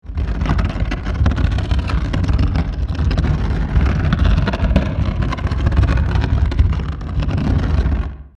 Wood Scrape; Heavy Wooden Object Drags Across Rough Surface With Rumble.